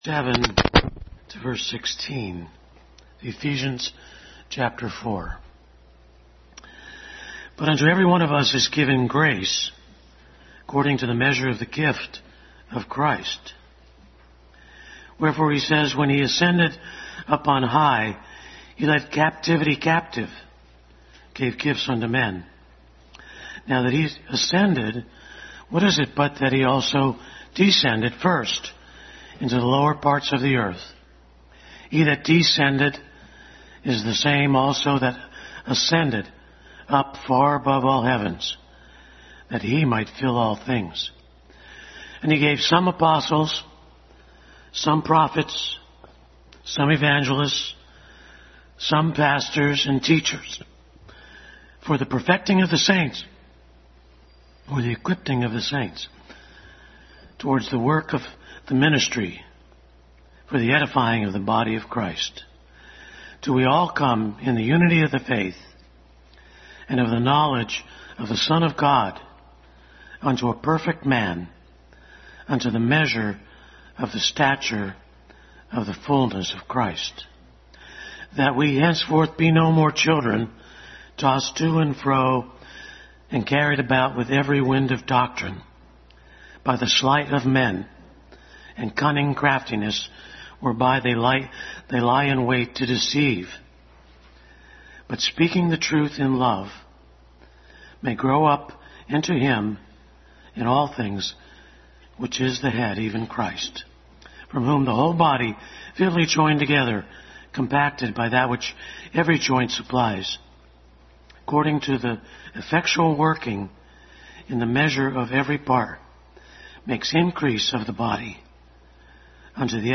Adult Sunday School Class continued study in Ephesians.